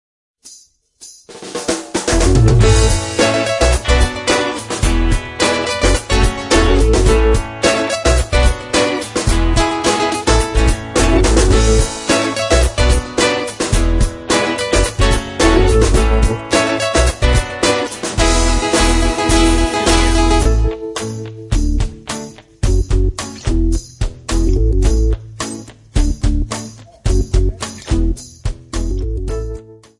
Jolly contemporary religious Songs sung by Children.